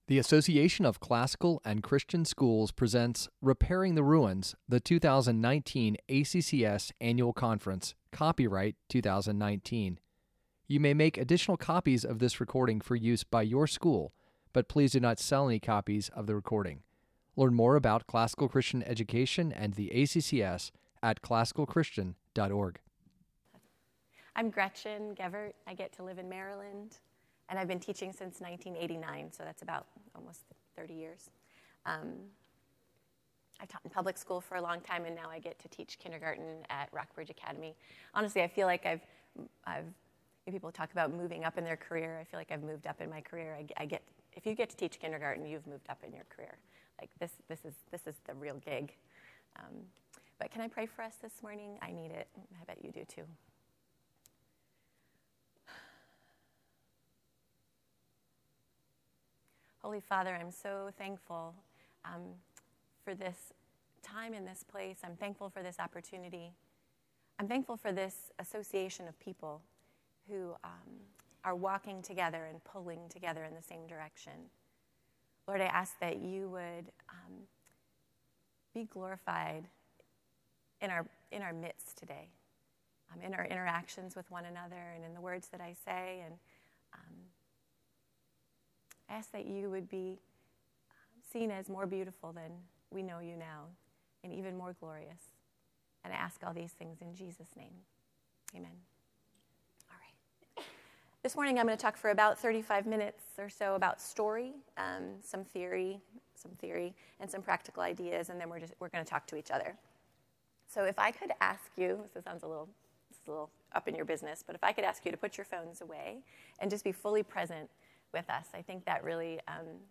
2019 Workshop Talk | 44:58 | K-6, Teacher & Classroom, General Classroom
This workshop is an interactive, hands-on session.